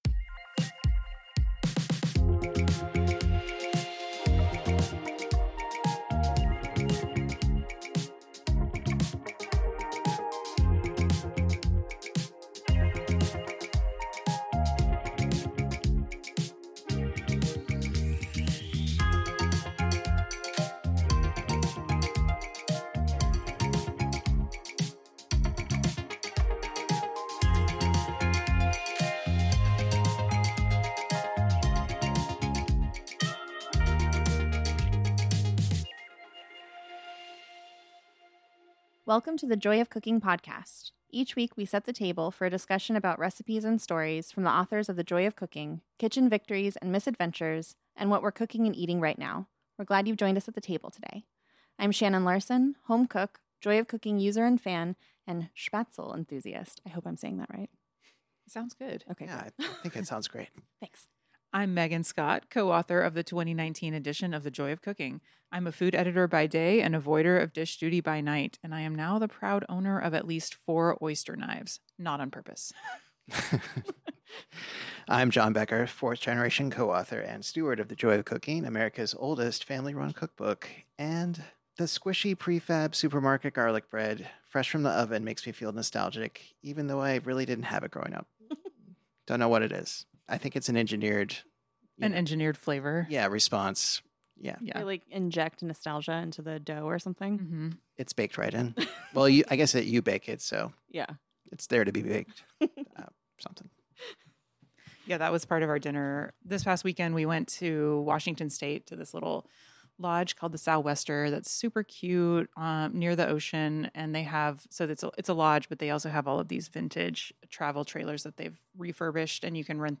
Join us at the table for a casual culinary chat about ramen.